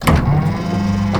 DOOR_Elevator_Open_01_stereo.wav